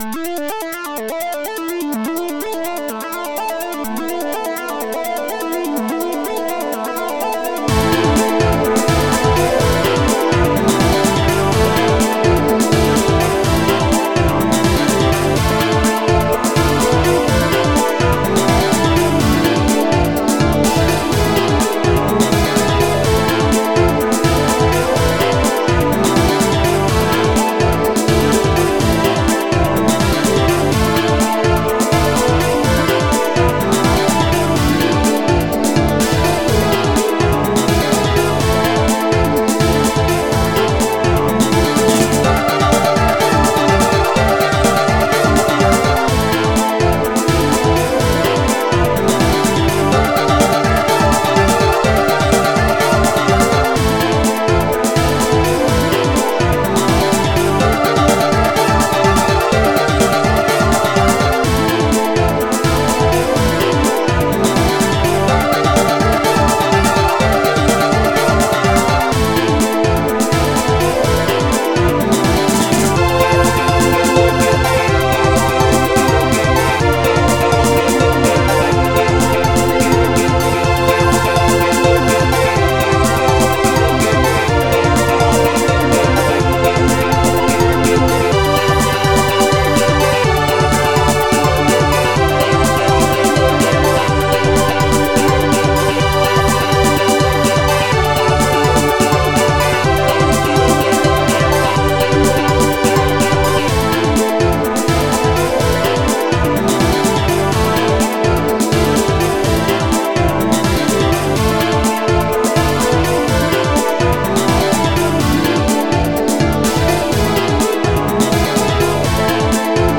Impulse Tracker Module